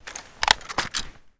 ak74_holster.ogg